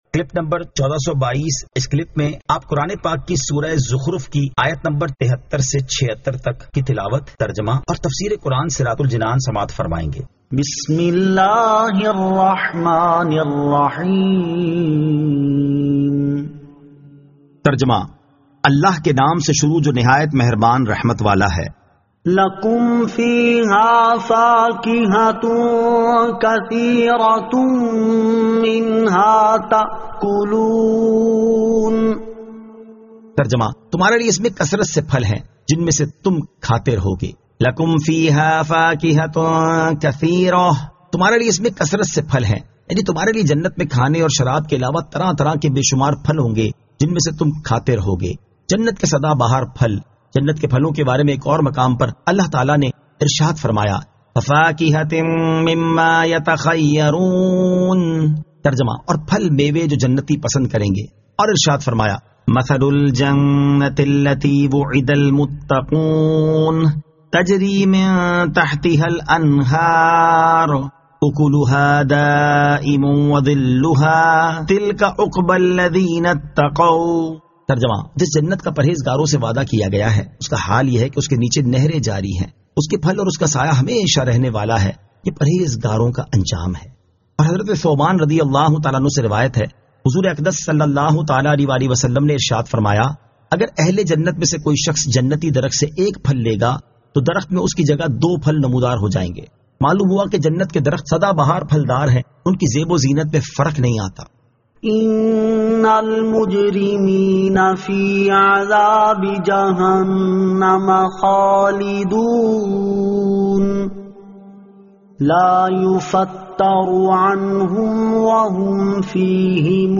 Surah Az-Zukhruf 73 To 76 Tilawat , Tarjama , Tafseer